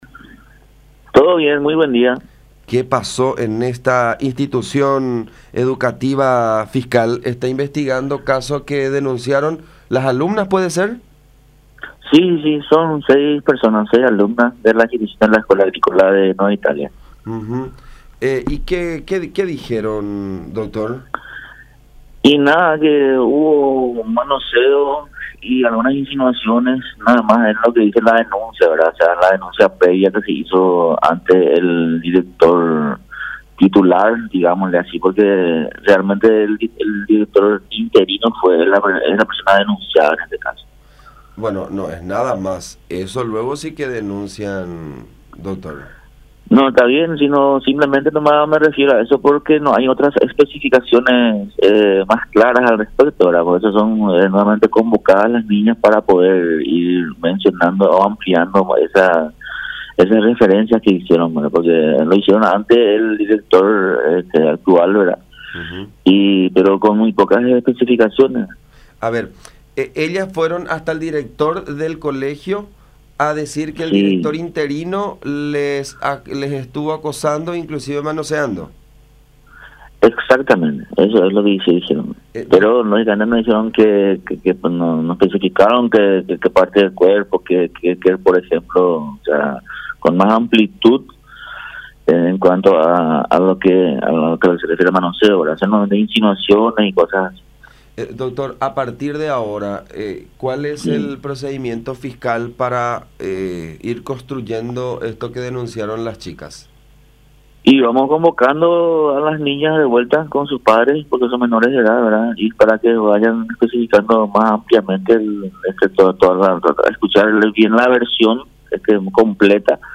“Manoseos y algunas insinuaciones es lo que dice la denuncia de las chicas, que son de entre 15 y 17 años”, explicó el fiscal que investiga el caso, Daniel Fleitas, en diálogo con La Unión, afirmando que el supuesto autor fue el director interino.